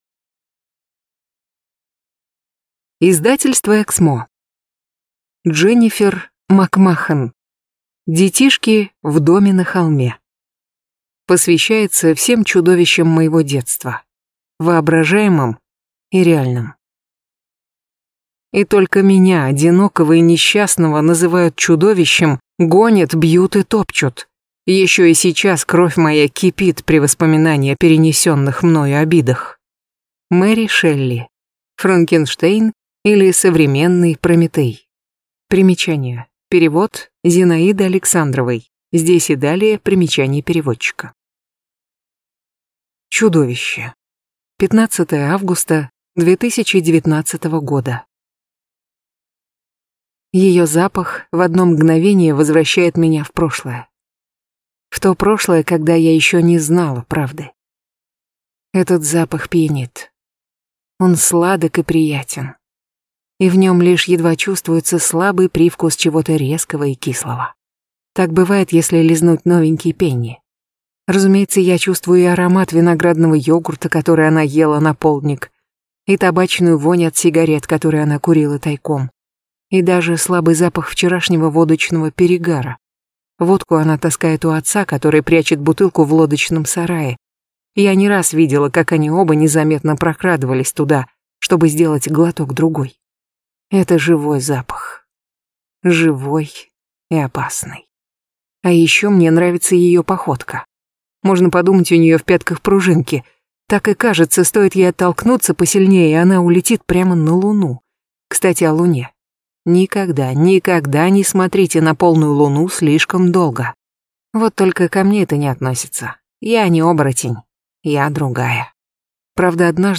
Аудиокнига Детишки в доме на холме | Библиотека аудиокниг